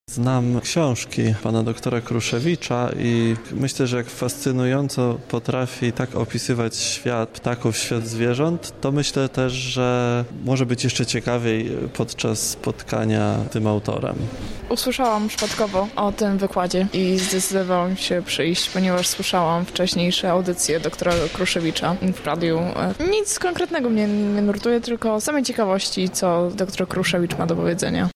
Nad wspólną egzystencją ludzi i zwierząt zastanawiali się dzisiaj słuchacze wykładu doktora Andrzeja Kruszewicza, autora wielu publikacji o przyrodzie, a także dyrektora warszawskiego zoo.